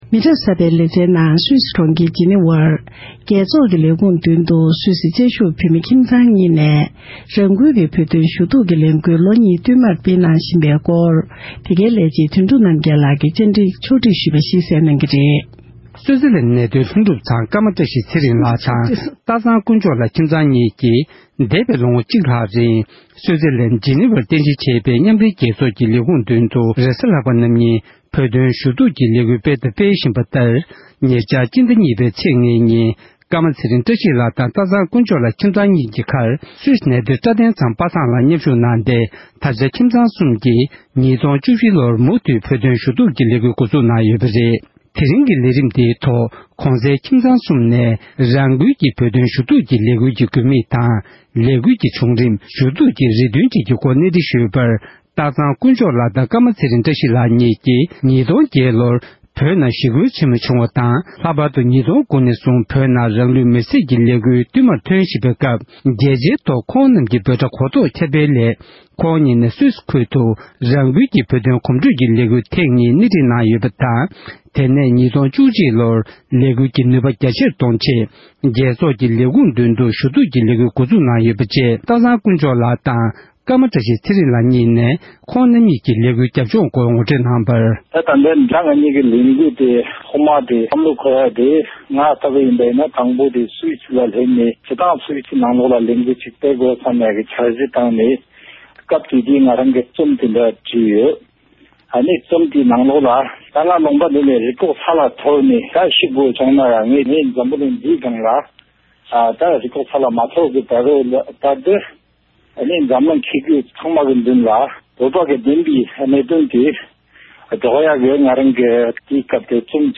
བཅའ་འདྲི་ཕྱོགས་བསྒྲིགས་ཞུས་པ་ཞིག